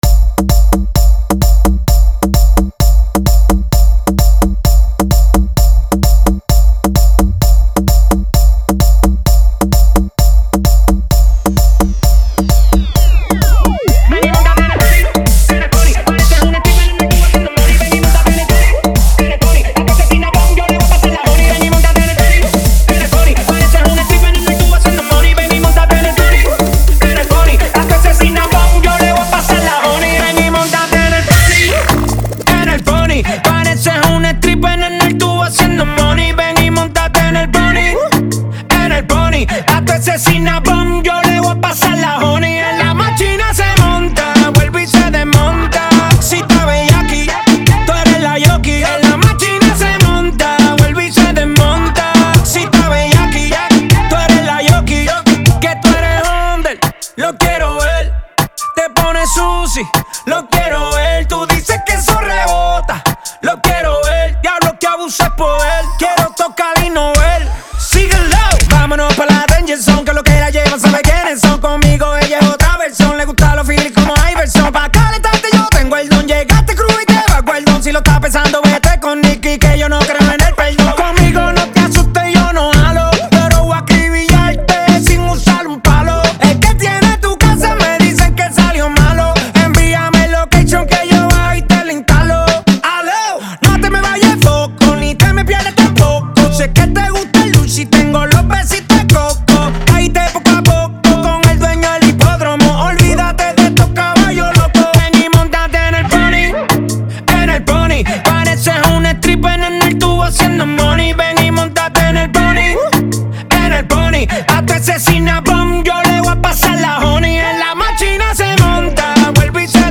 Genre: Transitions.